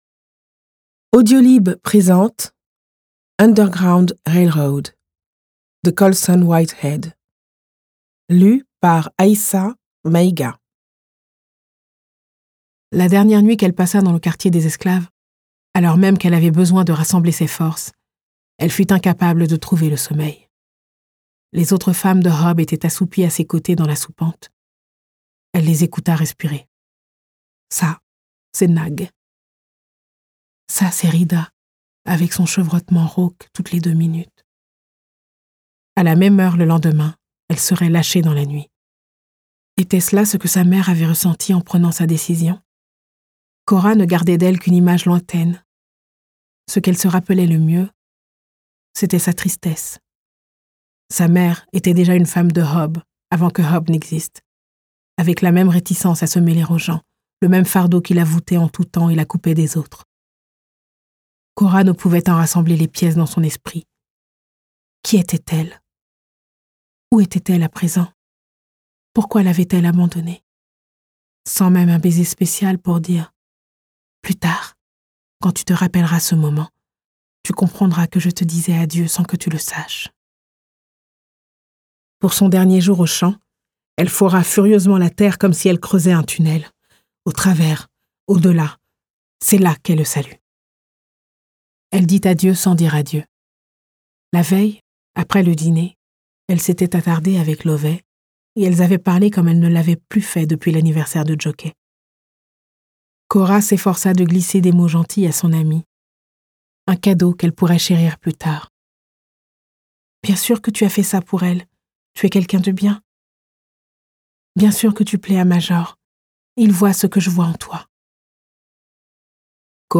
Lu par Aïssa Maïga
Underground Railroad de Colson Whitehead (extrait, lu par Aïssa Maïga)
Alors, cette voix féminine m’a tout d’abord surprise, puis m’a donné une nouvelle façon de voir ce texte, comme un nouveau point de vue. Elle a su ajouter une émotion supplémentaire, insuffler une autre énergie.
Mais Aïssa Maïga me l’a fait redécouvrir avec une grande sincérité, et une belle émotion.